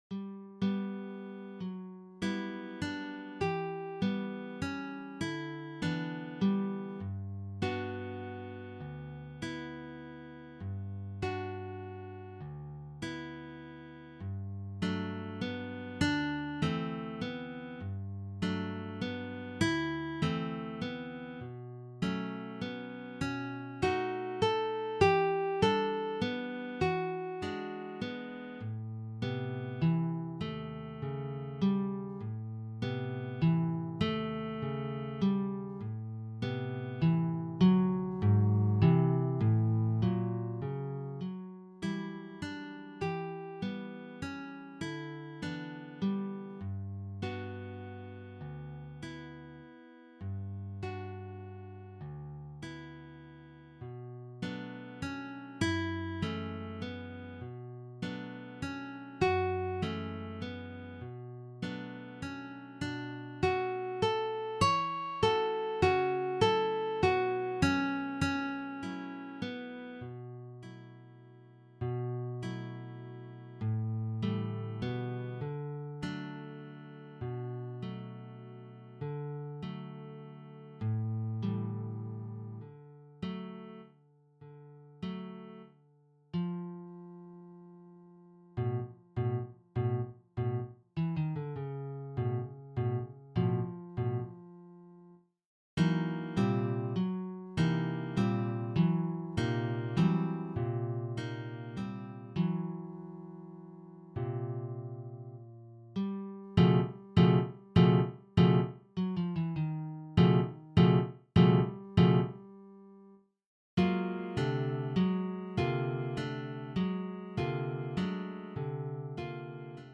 Guitar Quartet